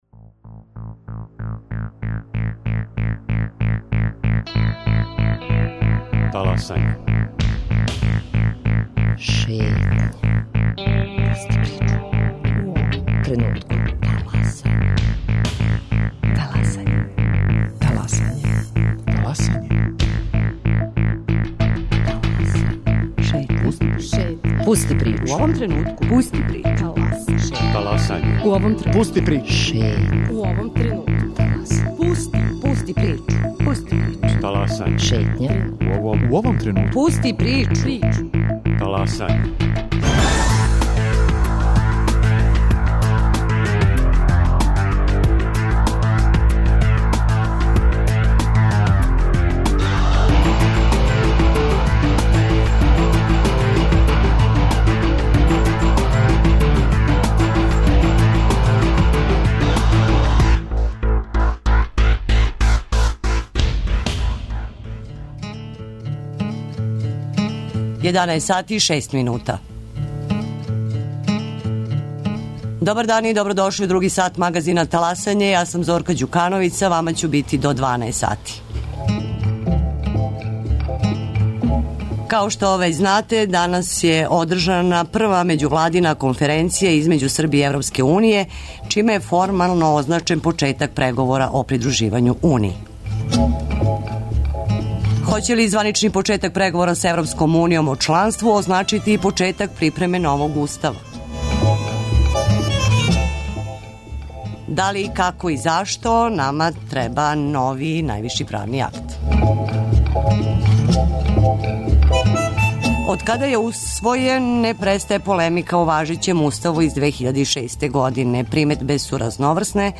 Расправу о уставним променама ће водити гости